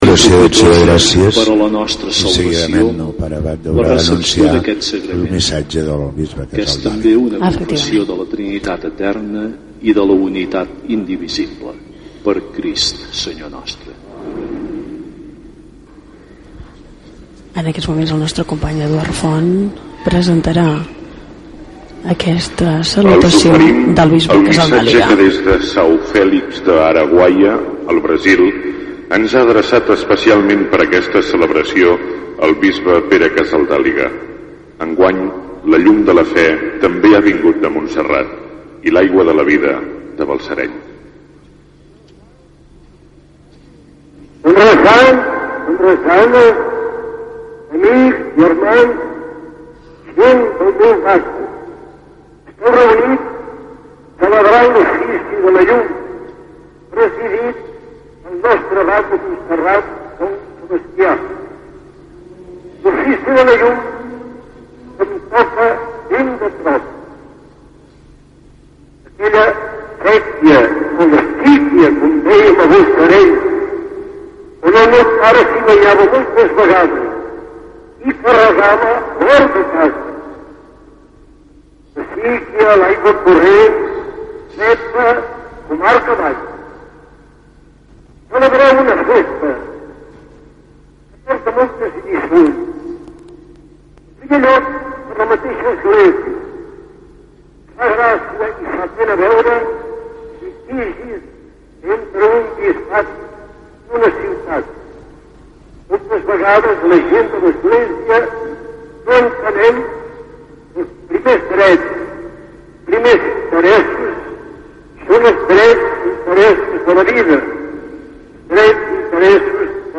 Salutació del bisbe Pere Casaldàliga a l'Ofici de la Llum de 1994
salutacio_pere_casaldaliga_1994.mp3